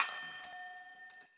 Closed Hats
Medicated Perc 2.wav